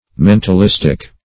mentalistic \men`tal*is"tic\, a.